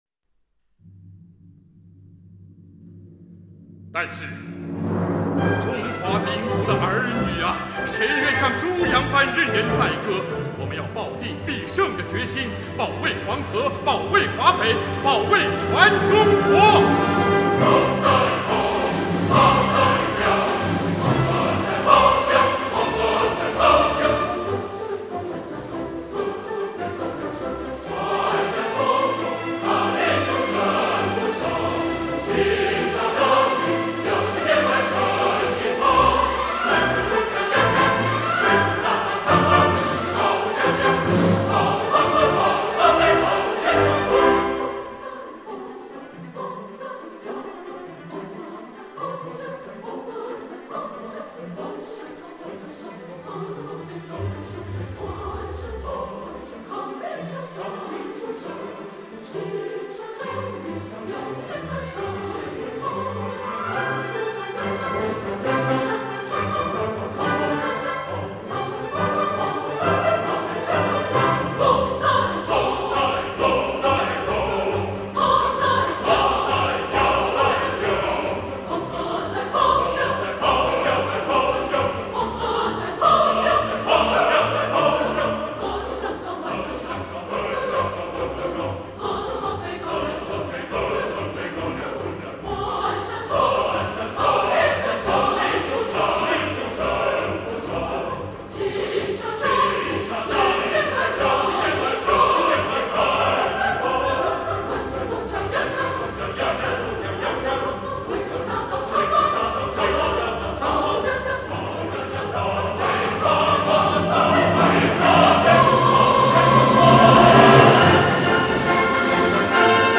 《黄河大合唱》气势磅礴，具有鲜明的民族风格，全曲包括序曲和8个乐章，并由配乐诗朗诵和乐队演奏将各乐平连成一个整体。